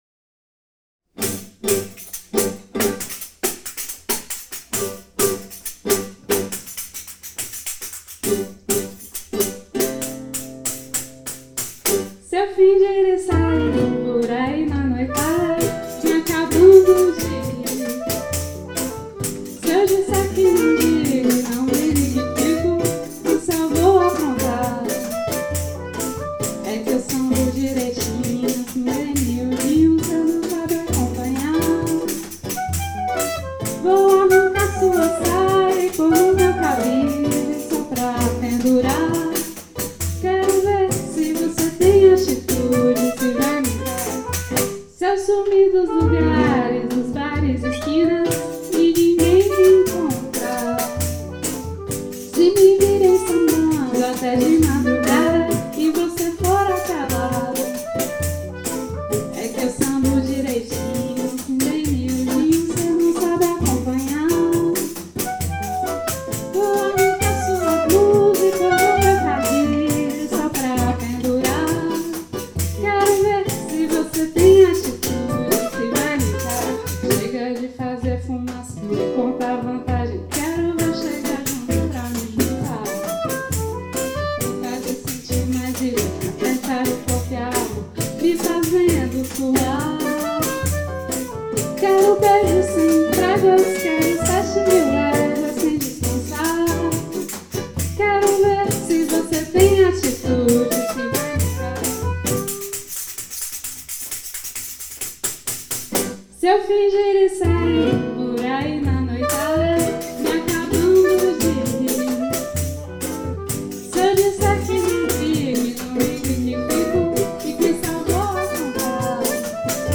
au cours d'un atelier de janvier 2023